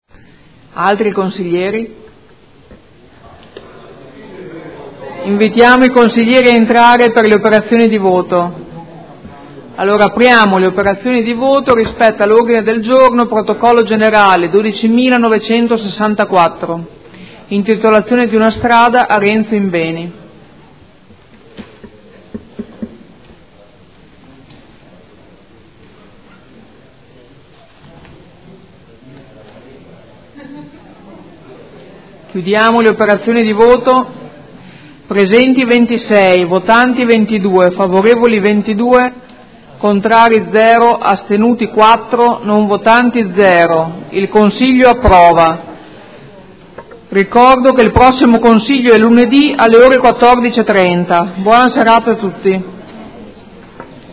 Presidente — Sito Audio Consiglio Comunale
Seduta del 26/03/2015.